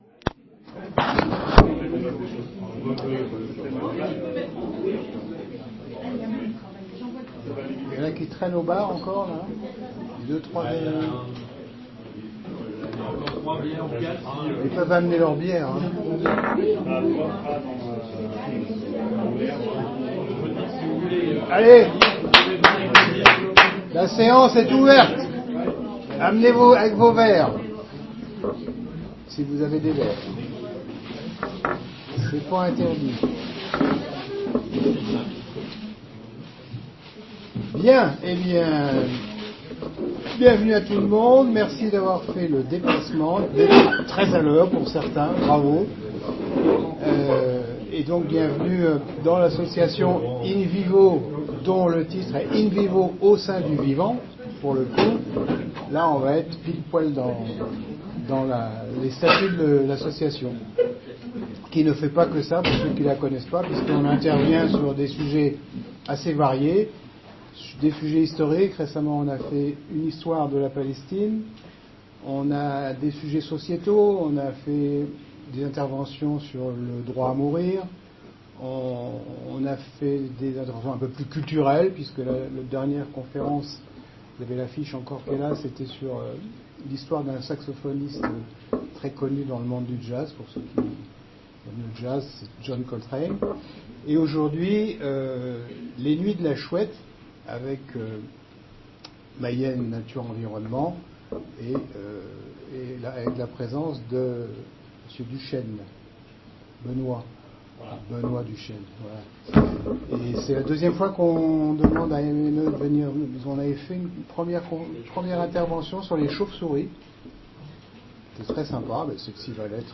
HISTOIRE DE LA PALESTINE VENDREDI 10 JANVIER VENDREDI 7 MARS VENDREDI 21 MARS 03-21_Conférence_Impact_des_Activités_Humaines_sur_les_Rapaces_Nocturnes Télécharger VENDREDI 25 AVRIL VENDREDI 16 MAI SAMEDI 8 NOVEMBRE
03-21_conference_impact_des_activites_humaines_sur_les_rapaces_nocturnes.mp3